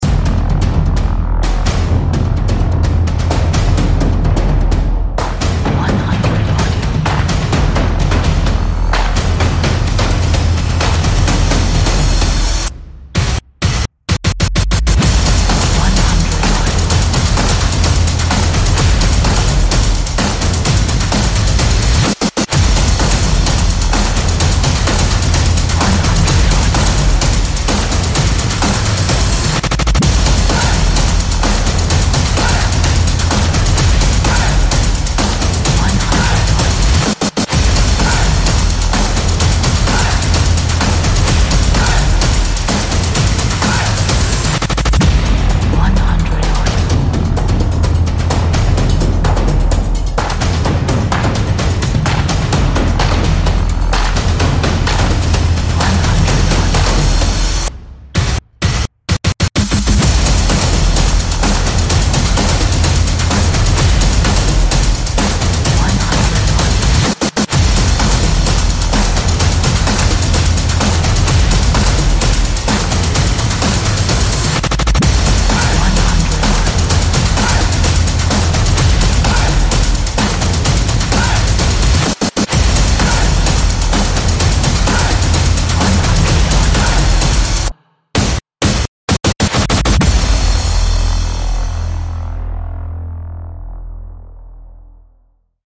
Epic energetic taiko drums with modern effects!